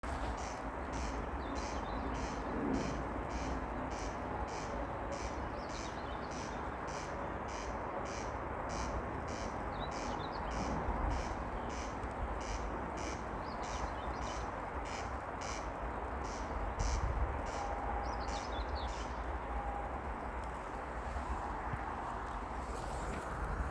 Corn Crake, Crex crex
StatusSinging male in breeding season
NotesGriezes šajā vietā iespējams dzirdēt jau vairāku gadu garumā katru maiju/jūniju.